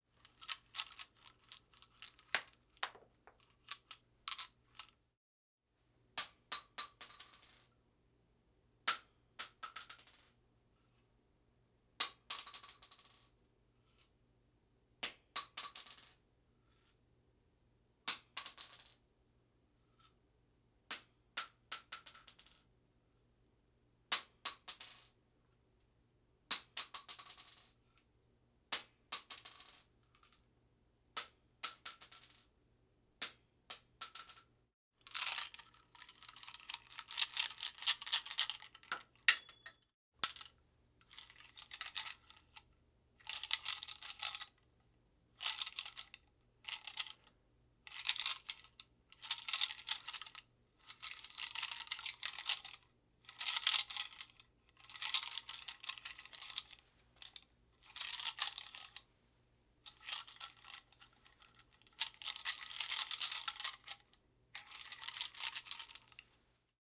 munition_shells
bullet casing ding impact jingle metal shell sound effect free sound royalty free Voices